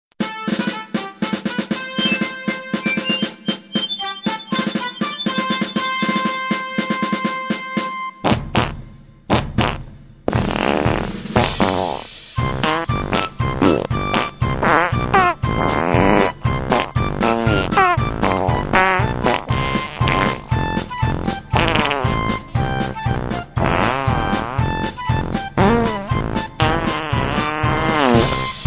• Качество: 128, Stereo
пуканье
пук